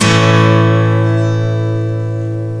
gitar.wav